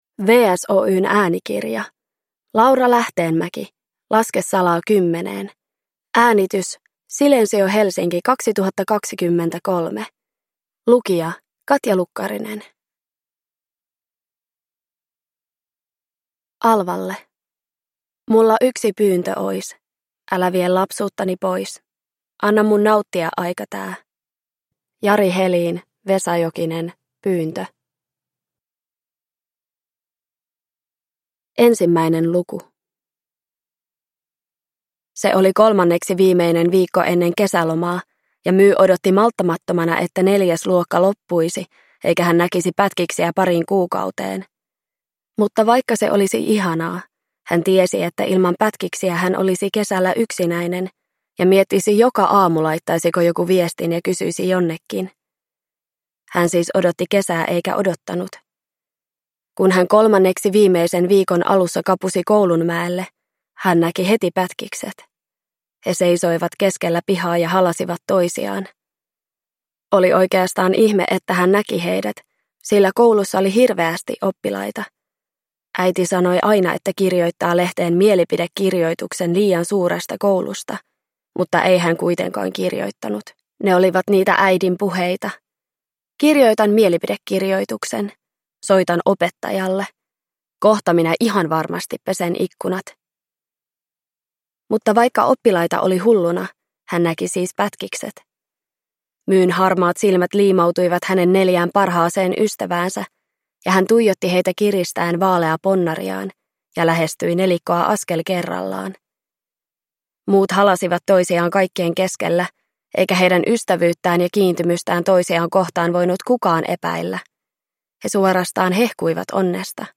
Laske salaa kymmeneen – Ljudbok